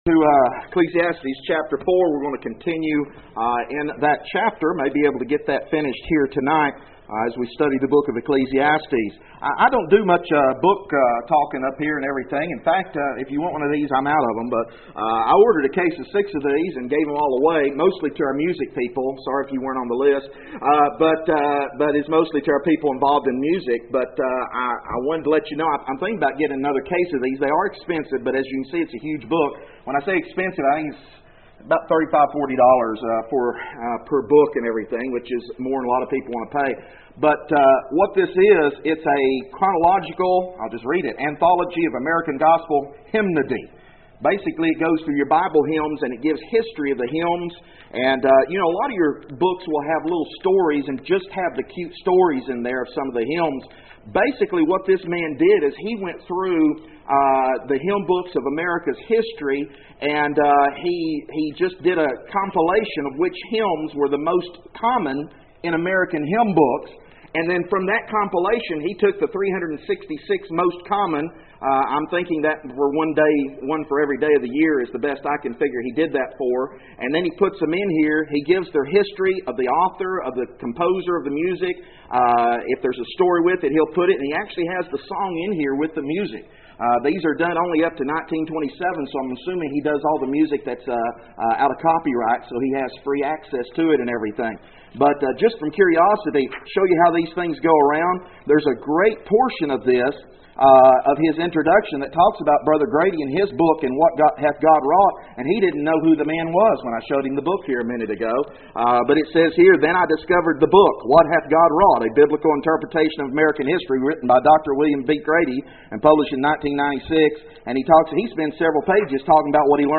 Text: Ecclesiastes 4:9-12 No Outline at this point. For More Information: More Audio Sermons More Sermon Outlines Join the Learn the Bible mailing list Email: Send Page To a Friend